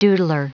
Prononciation du mot doodler en anglais (fichier audio)
Prononciation du mot : doodler